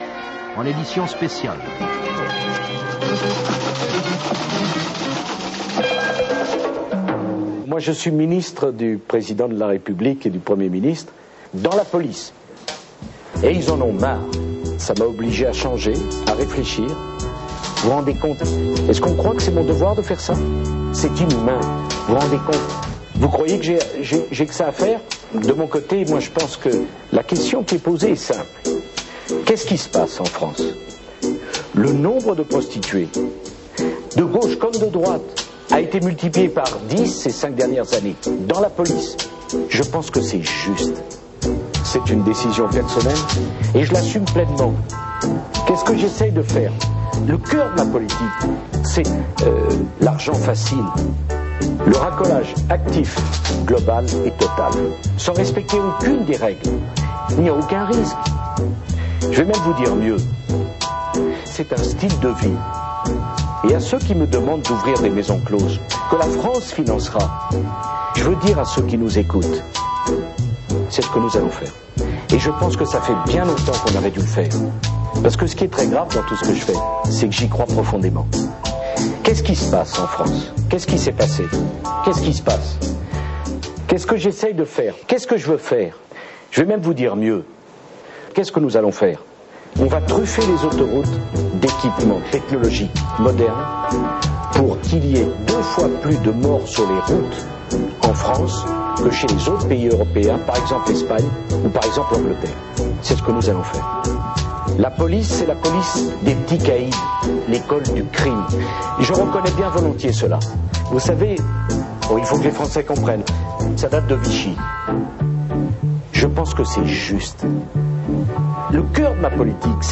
Pas mal, j'aime bien la musique derrière :p